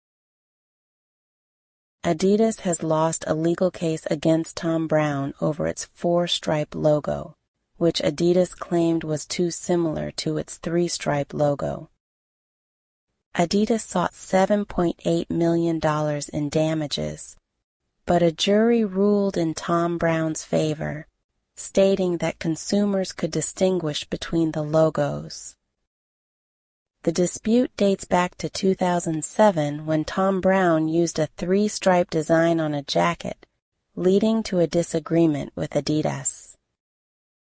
[BBC Summary]
[Listening Homework]
Try other AI voices